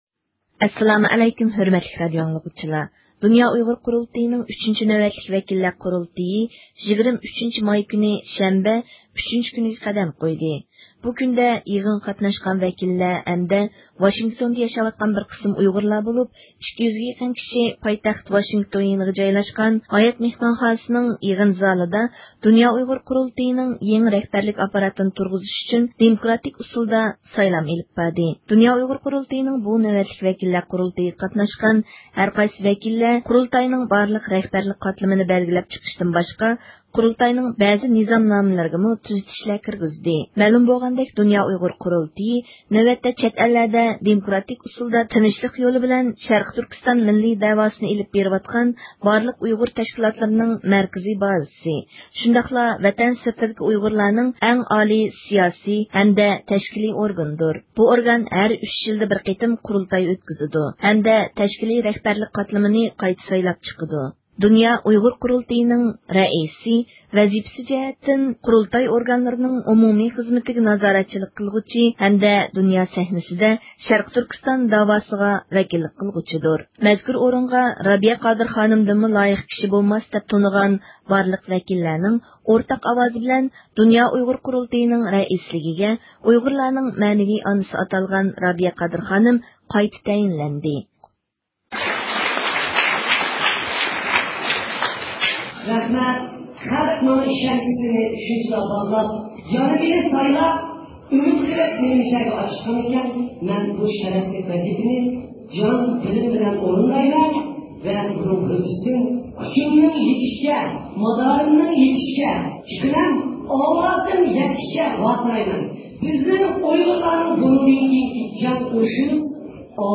رابىيە قادىر خانىم زىيارىتىمىزنى قوبۇل قىلىپ، مەزكۇر سايلامدا نامزاتلارنىڭ ئاكتىپ ئىپادىسدىن ئىنتايىن مەمنۇن بولغانلىقىنى ئىپادىلىدى.